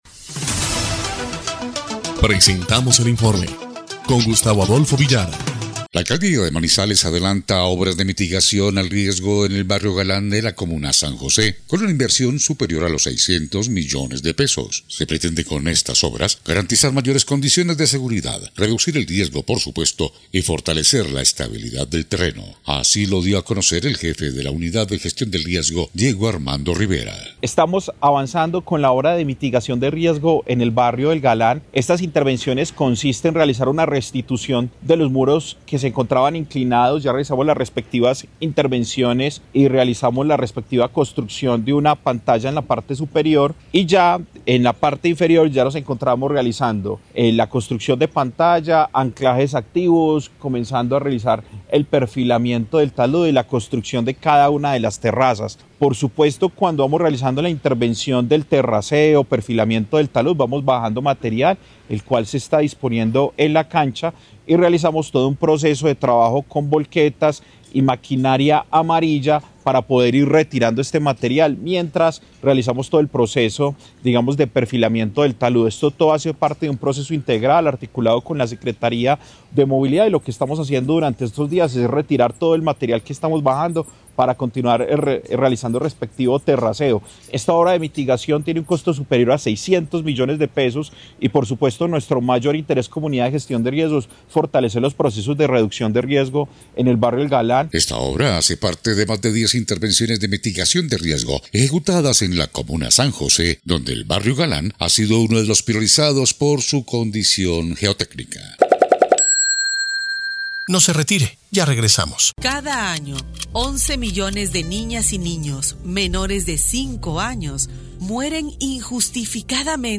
EL INFORME 3° Clip de Noticias del 18 de febrero de 2026